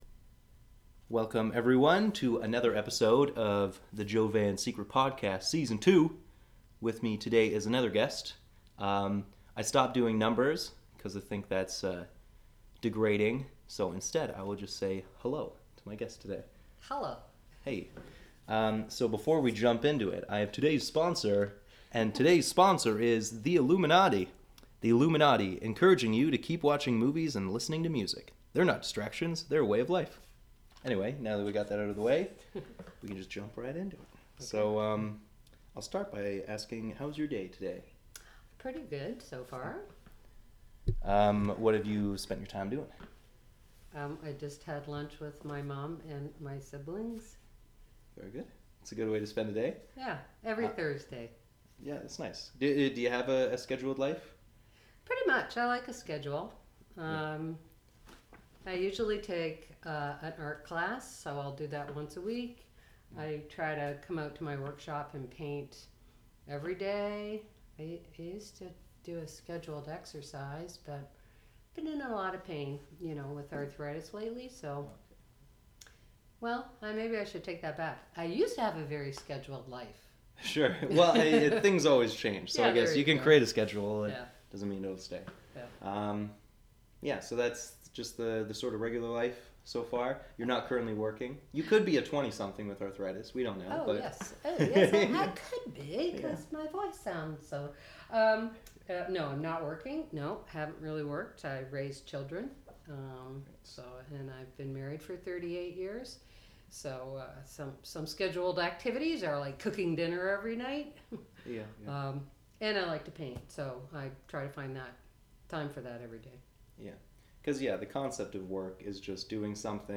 For podcast #28 I interview an artistic woman that has inspired me more than once. We talk about her journey into the art world, working with children, finding good in everyone and what might lie beyond.